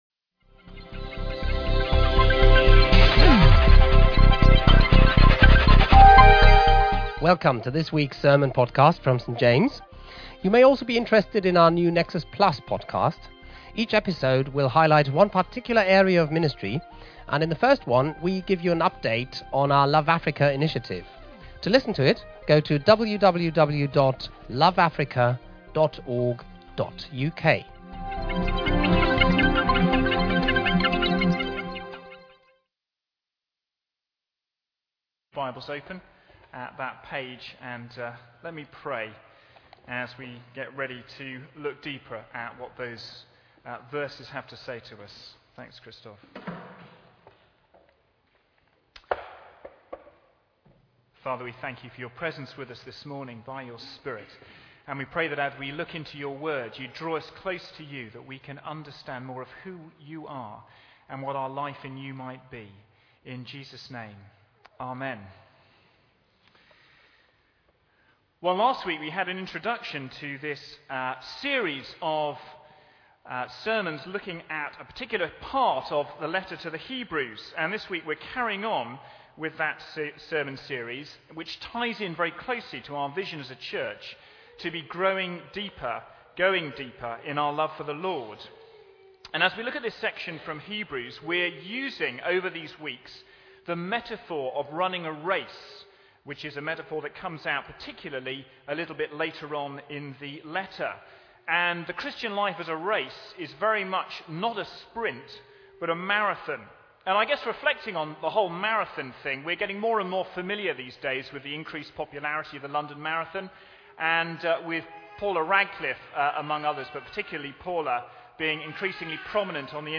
Talk given at St James Oxford Road 9:00 services